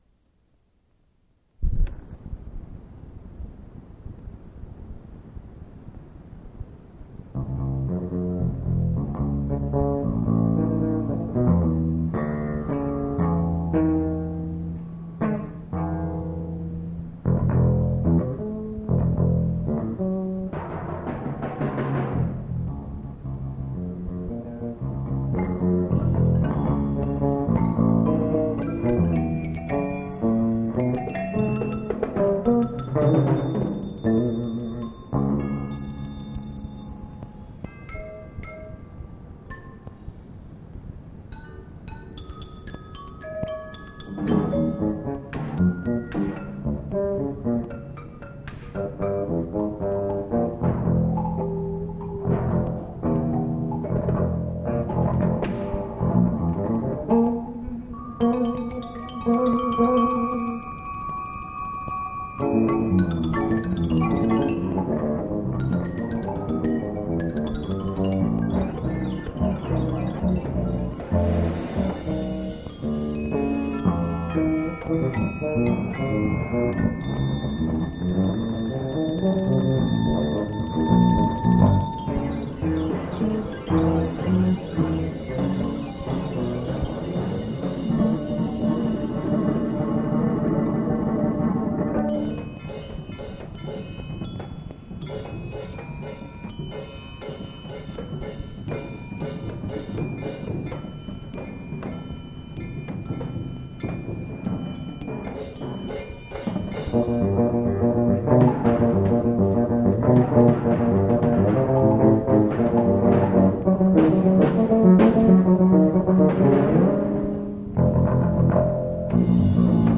Genre: Solo with Percussion Ensemble
# of Players: 9 + Solo Electric Bass
Solo Electric Bass
Percussion 9 (4 timpani, tam-tam)